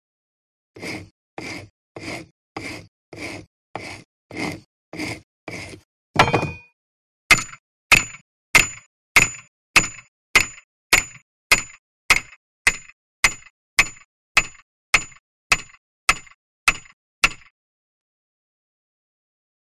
Horse Shoes; Filing & Shaping; Blacksmith Filing And Shaping Horse Shoes.